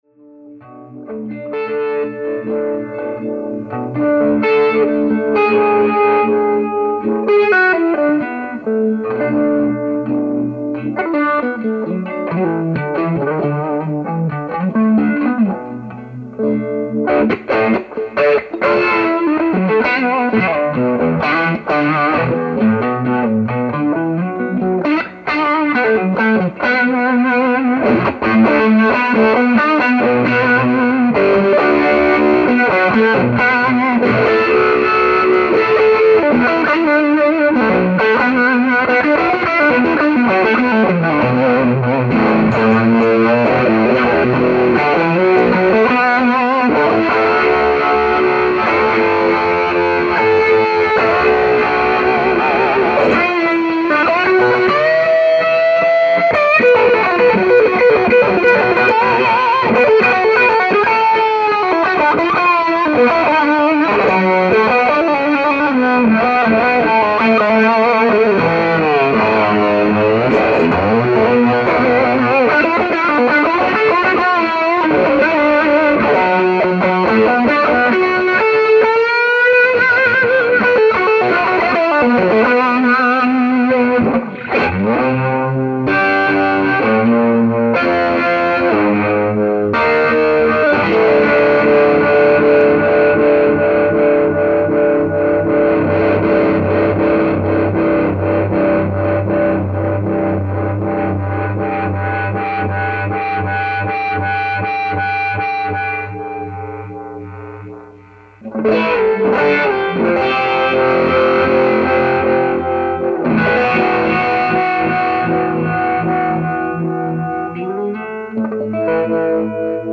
There were recorded with a typical crappy PC mic directly into my PC.
The volume is relatively low, & you can actually hear the clicking of the pedal switches, & pickup selector.
The second clip is similar, but I also used a Geofex Screamer in conjunction with the FD2.
I have it in the LED mode with the drive about 3 o'clock.
Once again, I kick in the fuzz somehere in the middle.
No EQ, or anything, & the sound is a bit dark, but.....